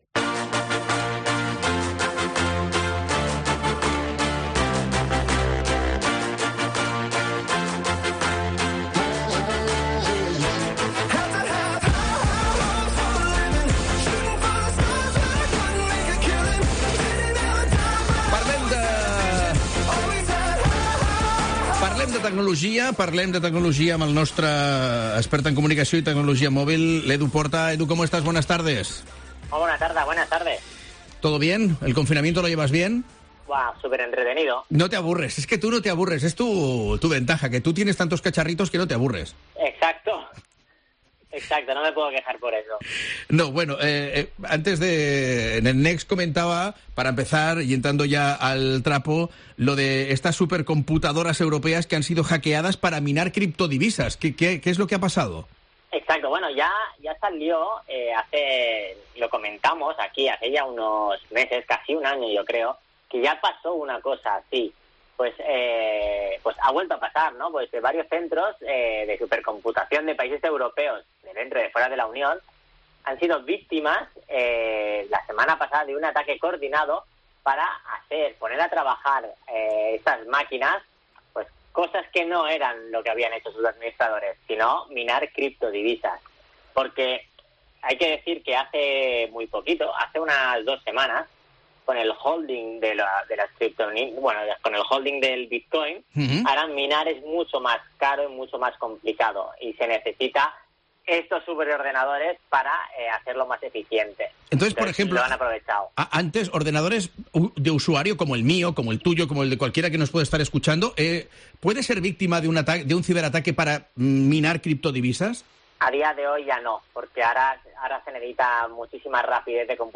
Cada martes nos explica la actualidad tecnológica en La Linterna Catalunya.